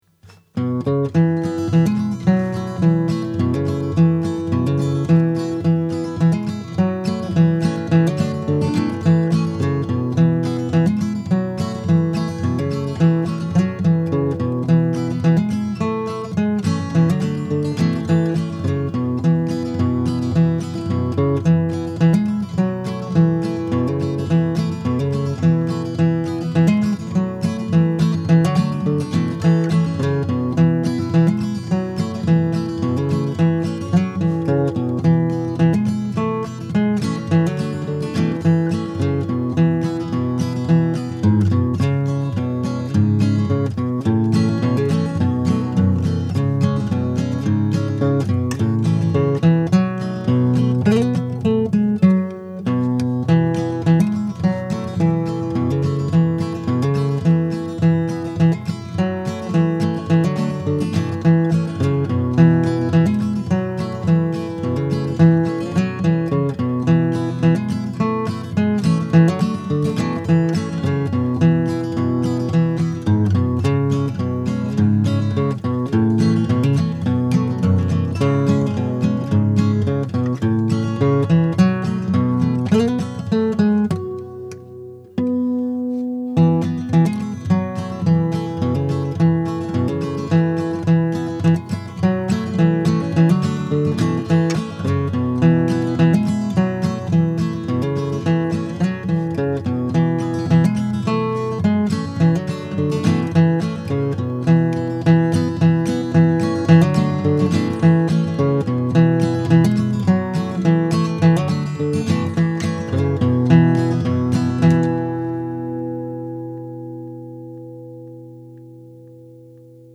But, to the best of my knowledge, “Father’s Day” has never been the title of a solo fingerstyle acoustic guitar instrumental piece… until now.